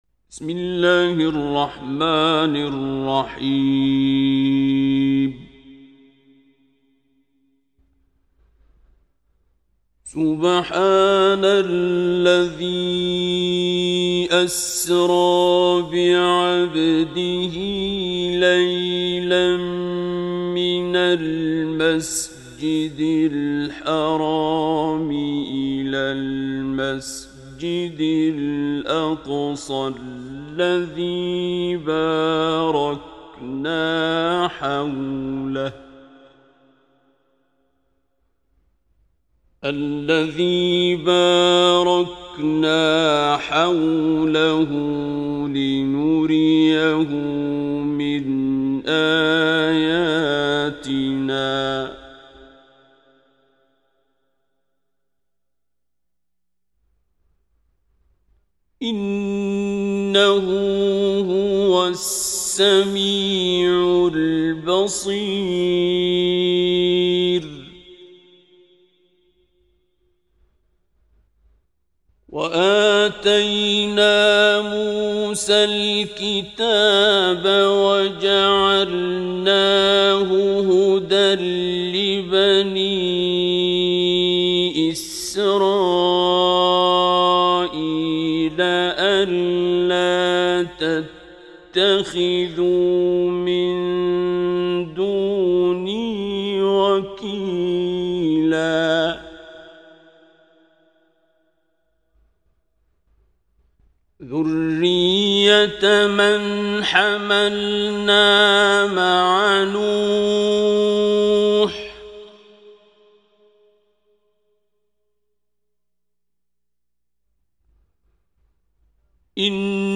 تلاوت زیبای سوره اسراء باصدای استاد عبدالباسط